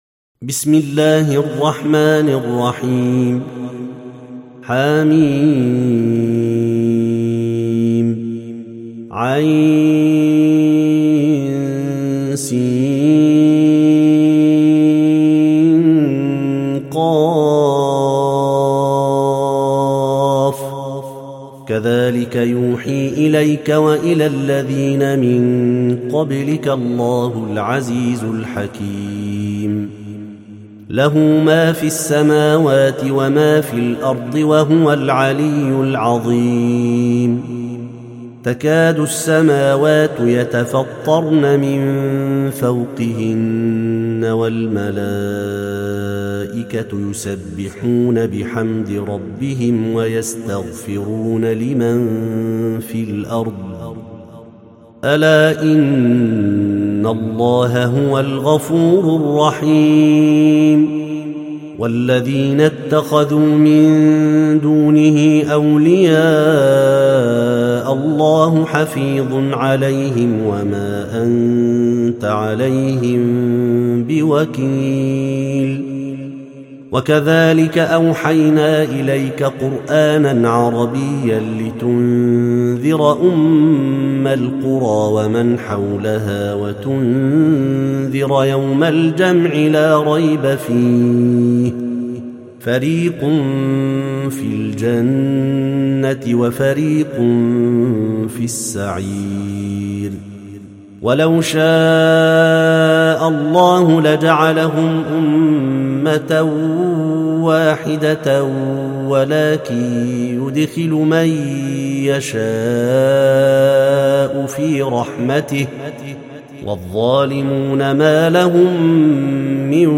سورة الشورى - المصحف المرتل (برواية حفص عن عاصم)